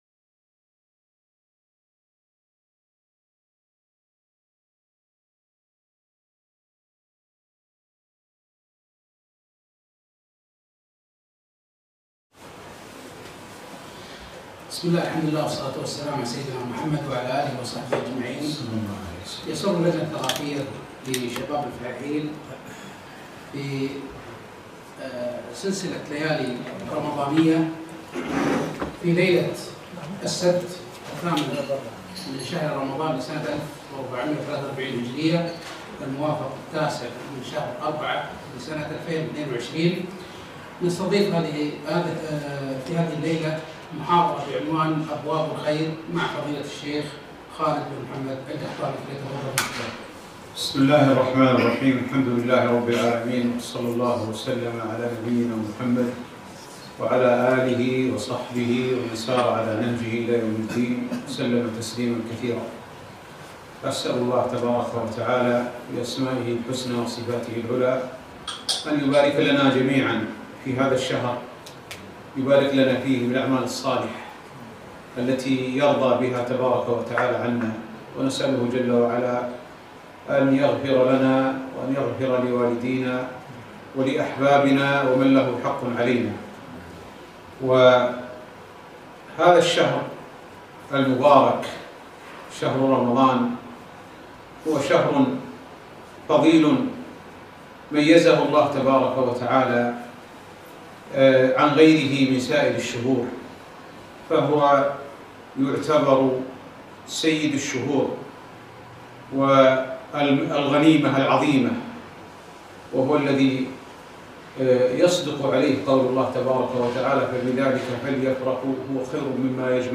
محاضرة - أبواب الخير